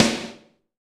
SNARE 022.wav